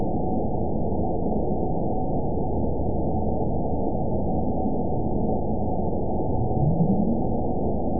event 920593 date 03/31/24 time 17:52:58 GMT (1 year, 1 month ago) score 8.96 location TSS-AB03 detected by nrw target species NRW annotations +NRW Spectrogram: Frequency (kHz) vs. Time (s) audio not available .wav